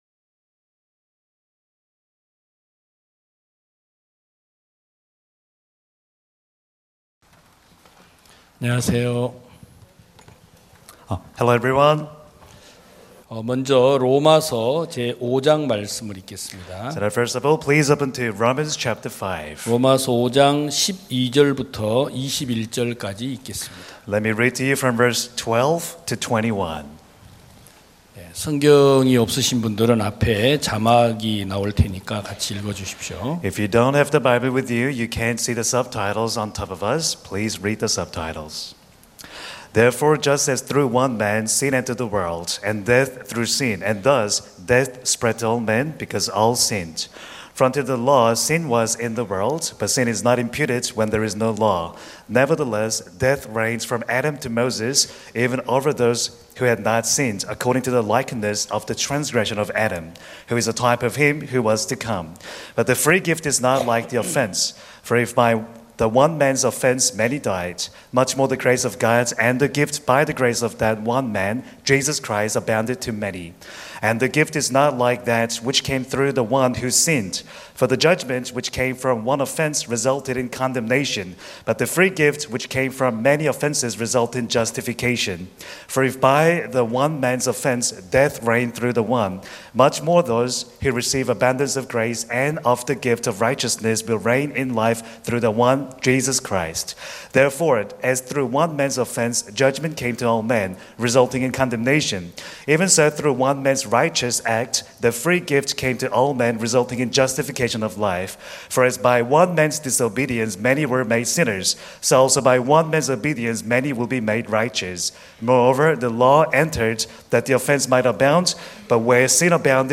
2022년 09월 04일 기쁜소식부산대연교회 주일오전예배
성도들이 모두 교회에 모여 말씀을 듣는 주일 예배의 설교는, 한 주간 우리 마음을 채웠던 생각을 내려두고 하나님의 말씀으로 가득 채우는 시간입니다.